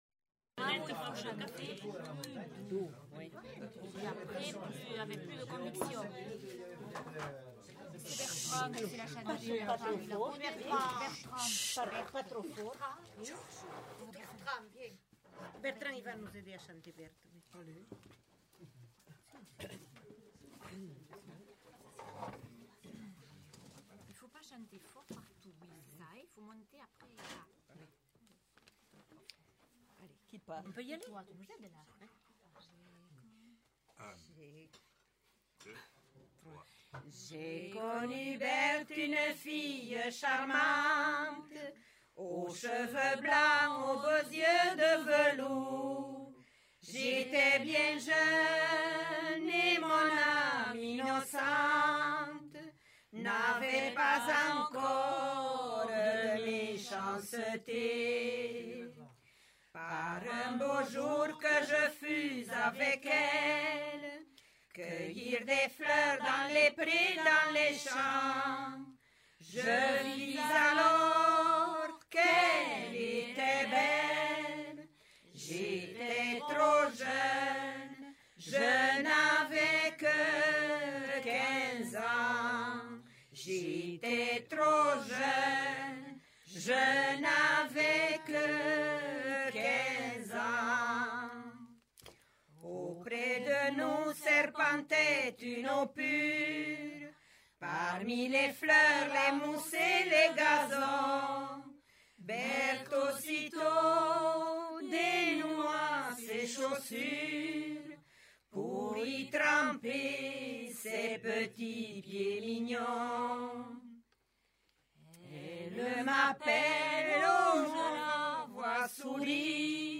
Aire culturelle : Bigorre
Lieu : Villemur-sur-Tarn
Genre : chant
Effectif : 4
Type de voix : voix d'homme ; voix de femme
Production du son : chanté